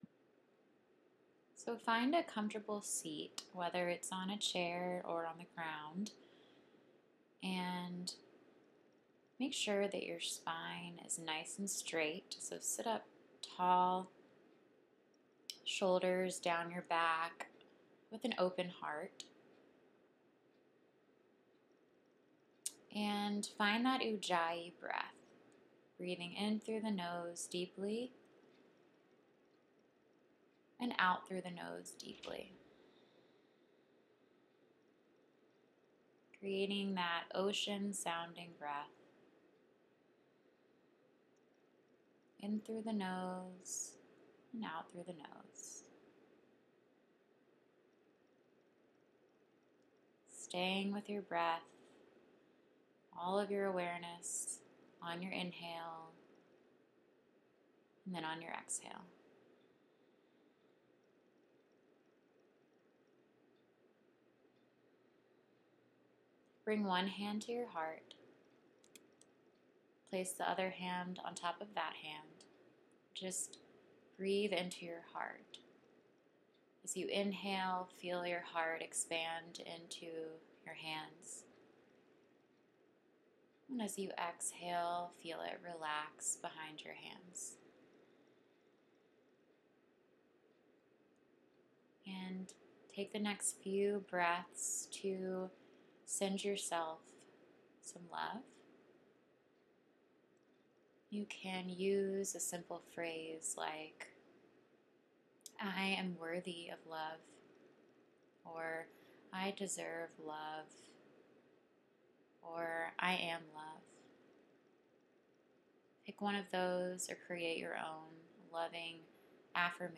loving kindness meditation.